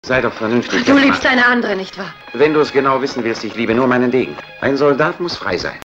Sound file of German dubbing actor (92 Kb)